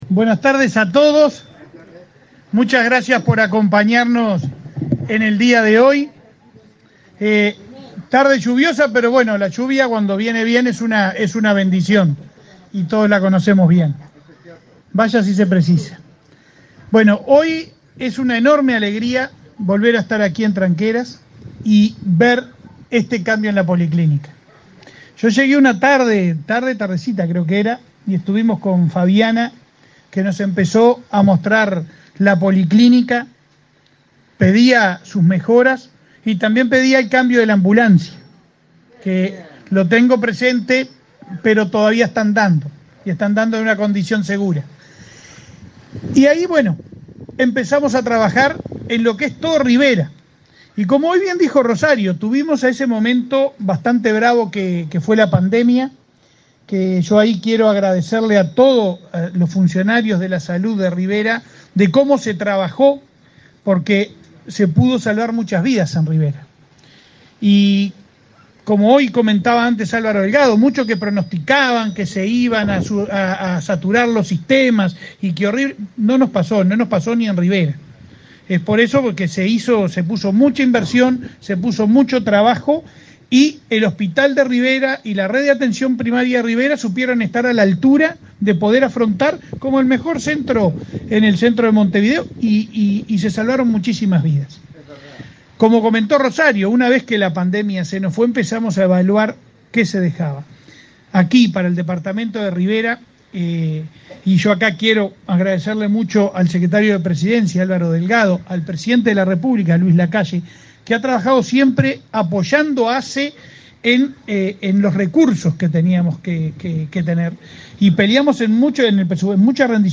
Palabras del presidente de ASSE, Leonardo Cipriani
Palabras del presidente de ASSE, Leonardo Cipriani 01/11/2023 Compartir Facebook X Copiar enlace WhatsApp LinkedIn La Administración de los Servicios de Salud del Estado (ASSE) inauguró, este 1.° de noviembre, las obras de remodelación de la policlínica de Tranqueras, en el departamento de Rivera. El presidente de ASSE, Leonardo Cipriani, disertó en el evento.